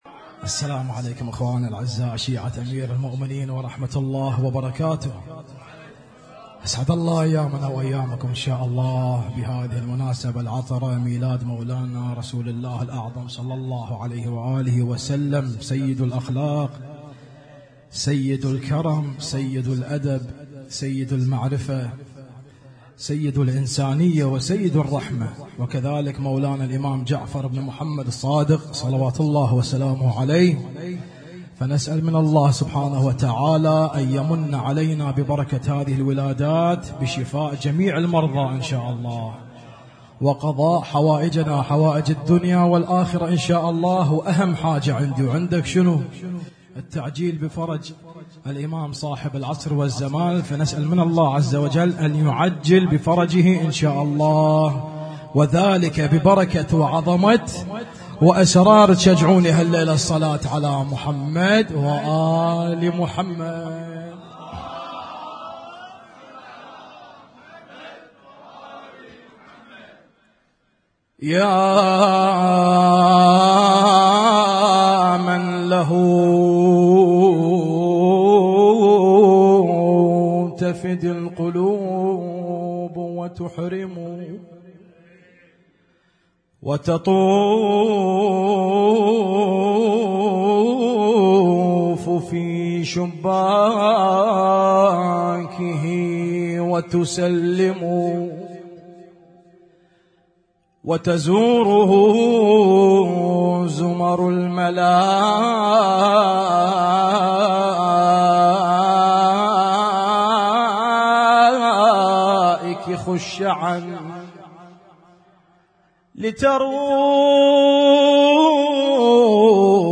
Husainyt Alnoor Rumaithiya Kuwait
اسم التصنيف: المـكتبة الصــوتيه >> المواليد >> المواليد 1444